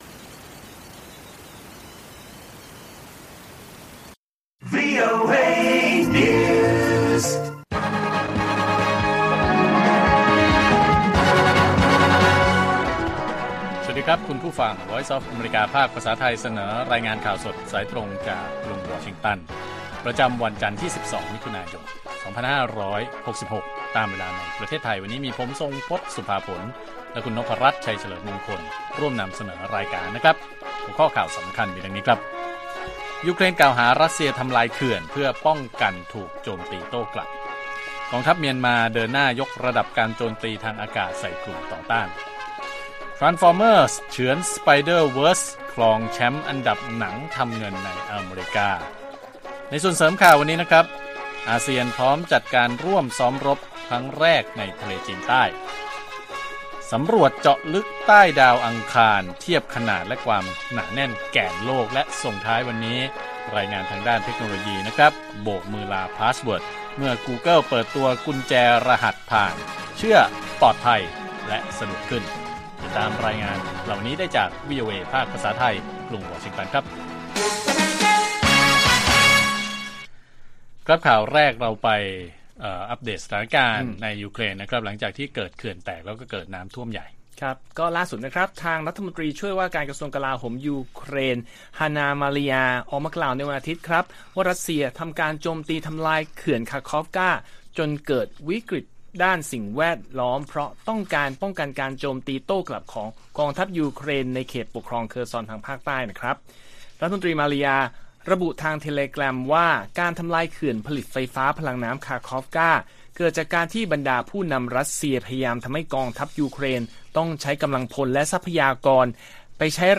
ข่าวสดสายตรงจากวีโอเอไทย 6:30 – 7:00 น. วันที่ 12 มิ.ย. 2566